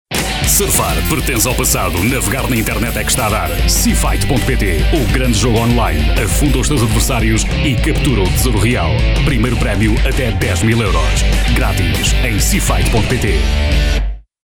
Portugiesischer Sprecher.
Sprecher portugiesisch.
Sprechprobe: Industrie (Muttersprache):